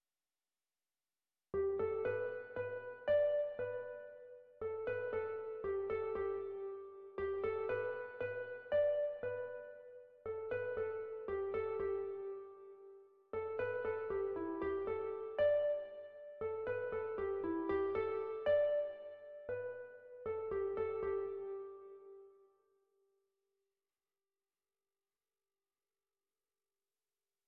dit liedje is pentatonisch